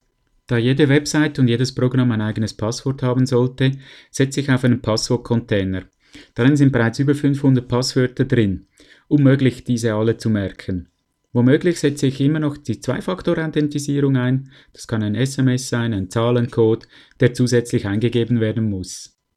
Das wollen wir von unserem Experten wissen.